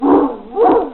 dog_fetch.mp3